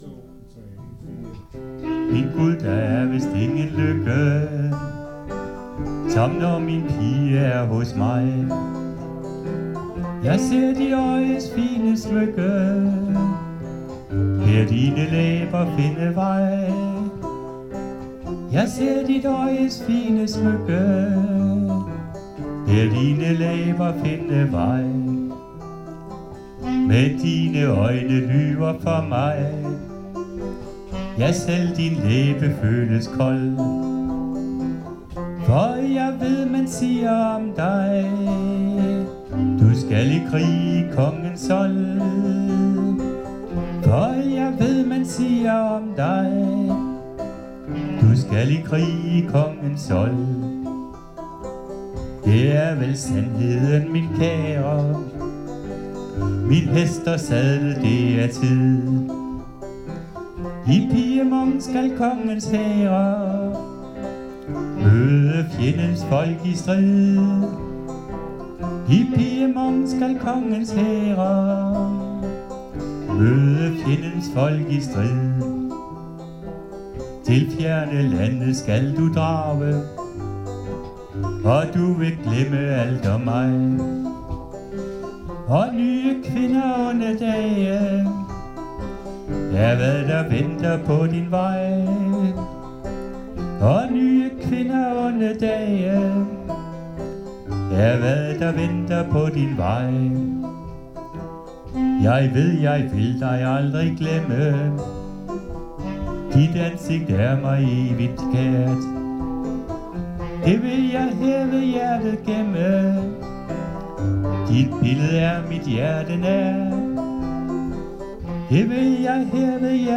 Øveaften 16. oktober 2024: